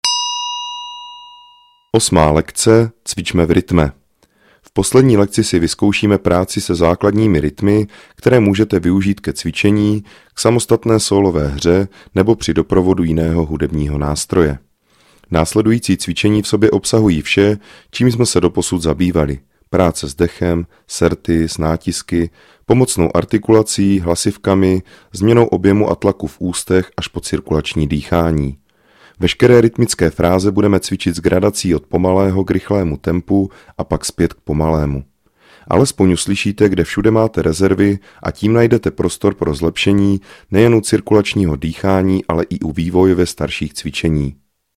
VÝUKA HRY NA DIDGERIDOO I.
Didgeridoo je nástroj, jehož hluboký tón dokáže ukotvit pozornost v přítomném okamžiku.
Track 34 - 8 lekce - Cvicme v rytme.mp3